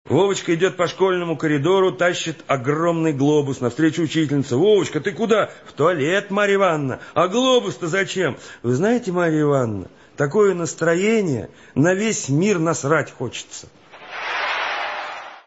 Звуки анекдотов